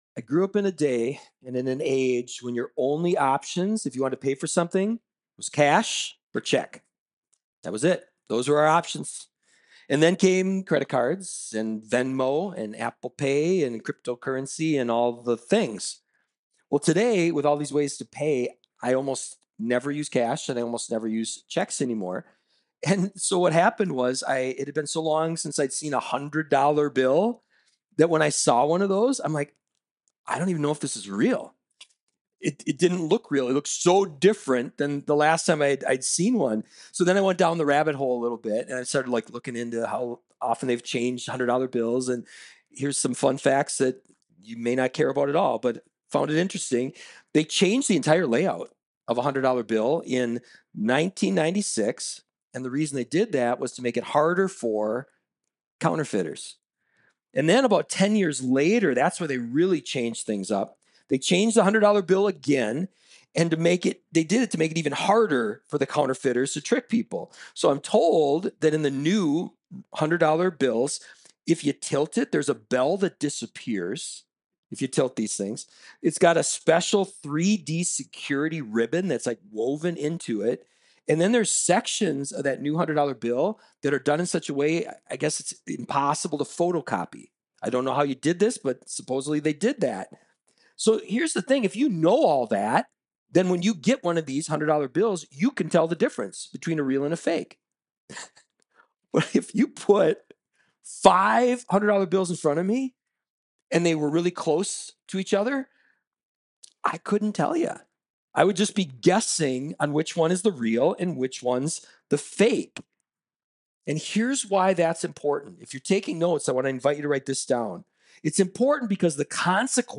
Acknowledge the Son These Things Watch Message By